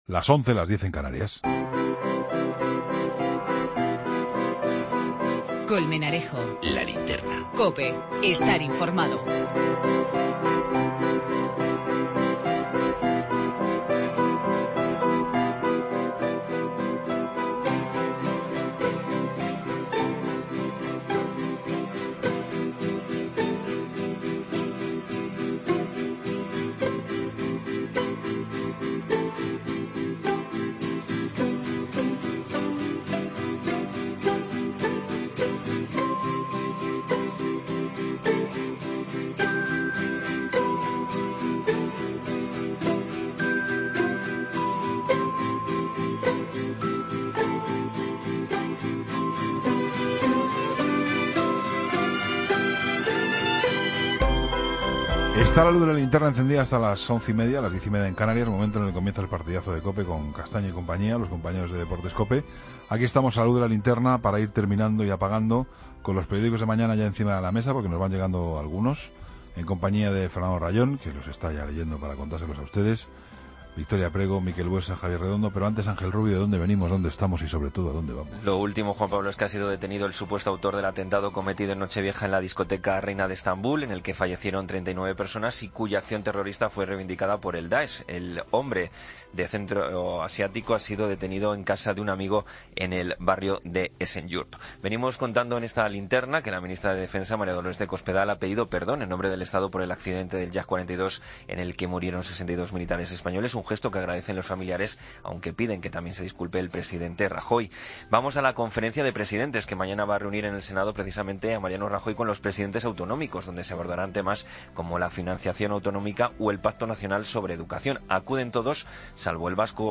La Tertulia